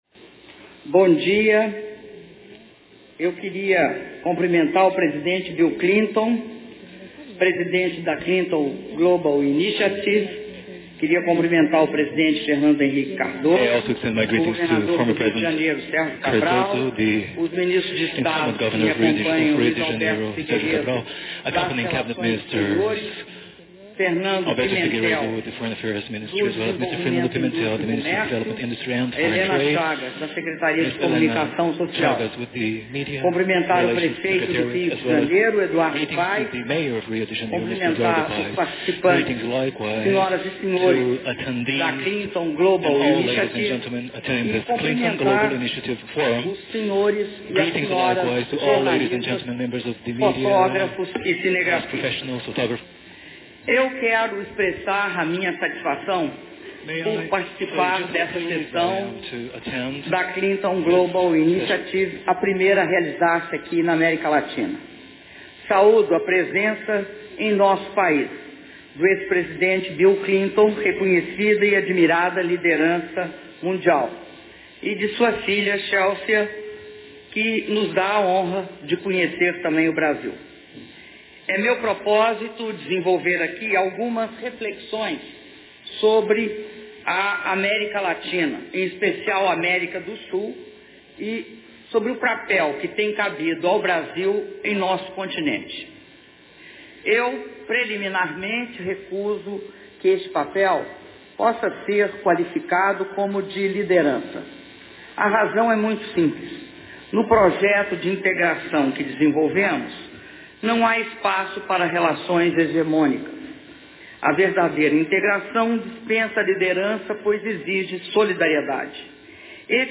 Áudio do discurso da Presidenta da República, Dilma Rousseff, durante encontro da Clinton Global Initiative (SGI) - Rio de Janeiro/RJ (21min34s)